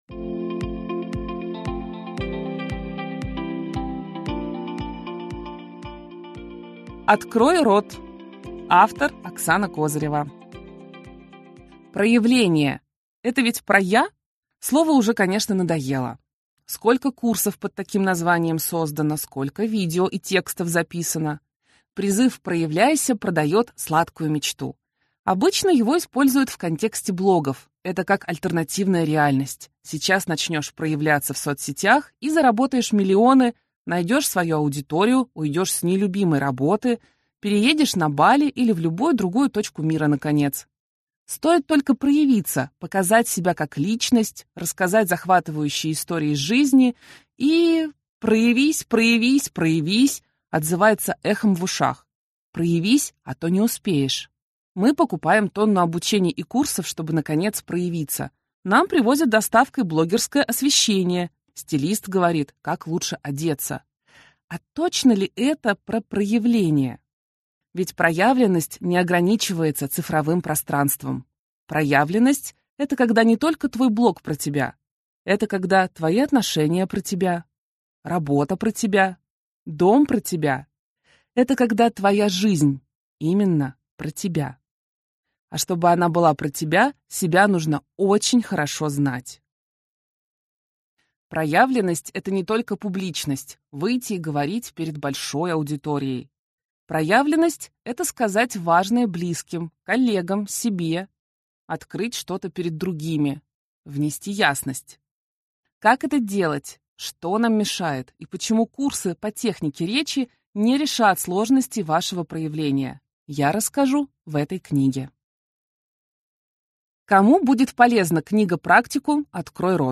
Открой рот. Проявляйся, говори и получи то, что хочешь (слушать аудиокнигу бесплатно